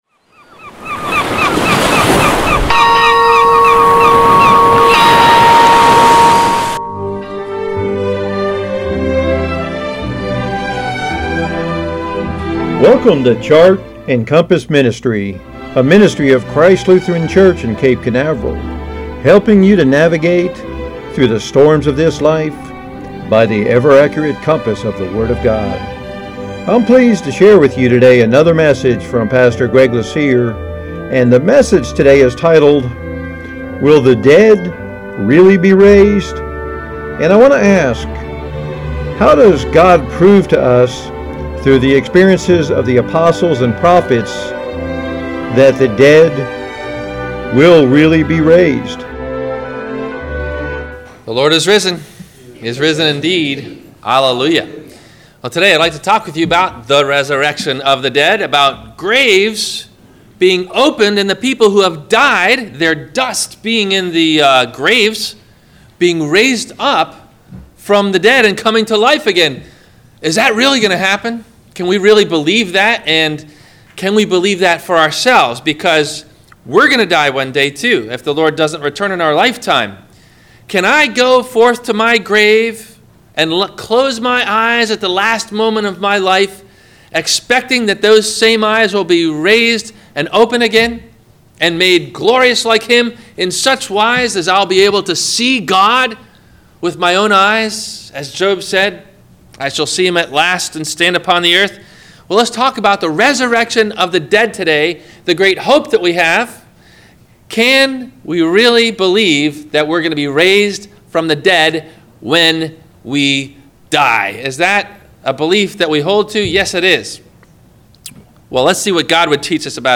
Questions asked before the Sermon message: